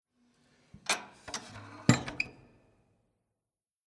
Closing the inner door
0578_Innere_Tuer_schliessen.mp3